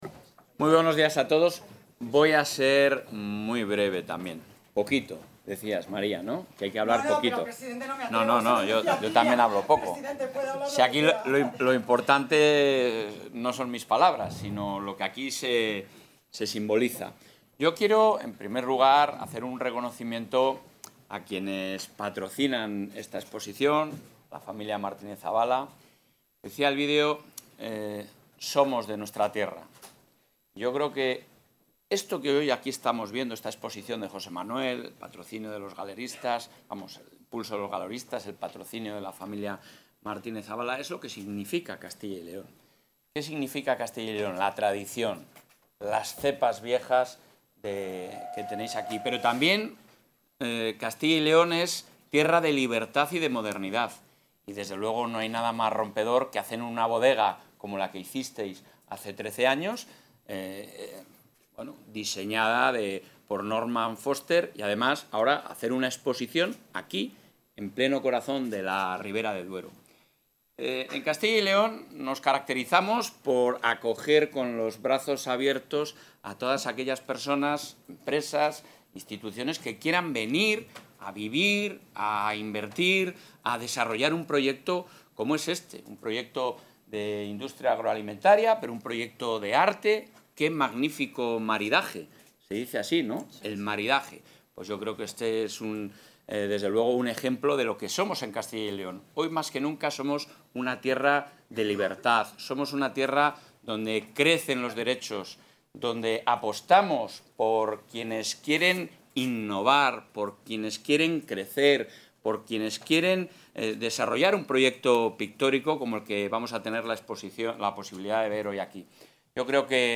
Intervención del presidente de la Junta.
El presidente autonómico, Alfonso Fernández Mañueco, ha visitado hoy, en Burgos, las Bodegas Portia, donde José Manuel Ciria, uno de los pintores contemporáneos con mayor proyección internacional, expone su obra.